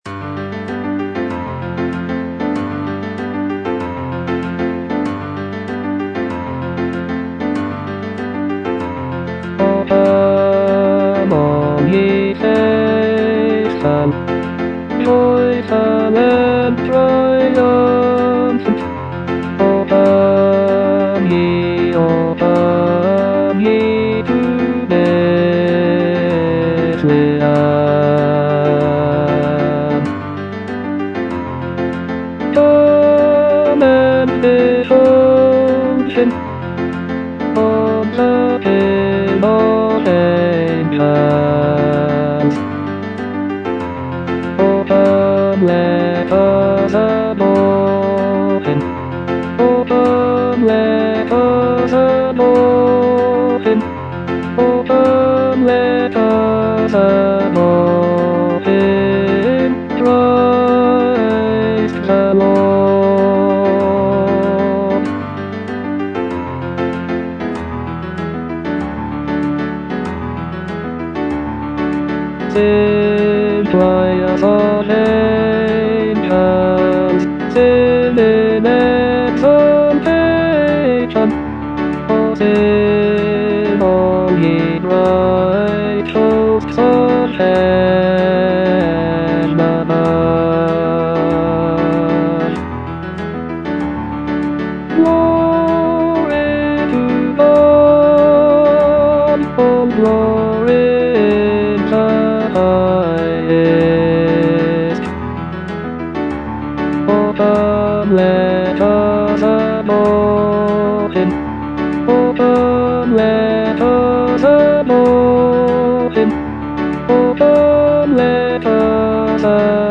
Bass (Voice with metronome)